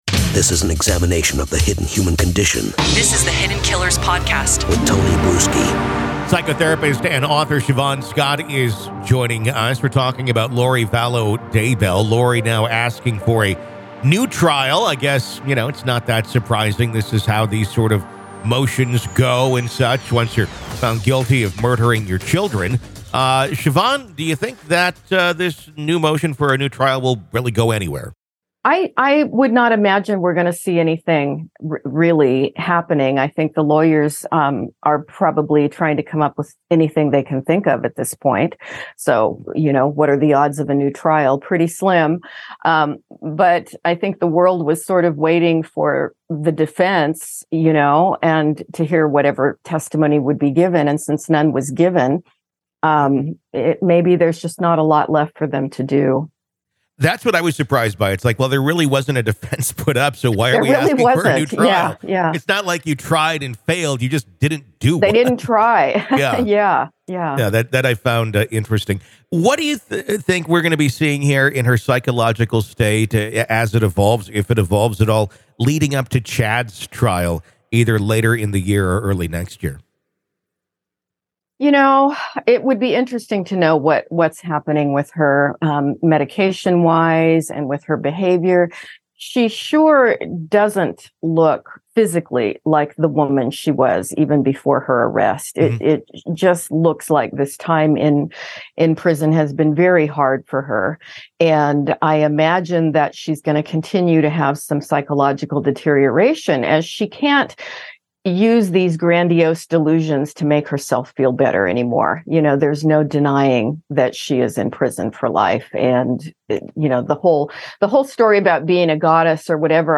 They explore the legal circumstances and precedents that could impact the likelihood of this happening. While considering the evidence presented in the original trial, they also discuss the potential grounds for appeal that might be used to justify a retrial. Their conversation provides an enlightening perspective on the complex legal processes involved in high-profile criminal cases like Vallow Daybell's. Share Facebook X Subscribe Next Why Do So Many Cults Start As Offshoots Of The LDS Church?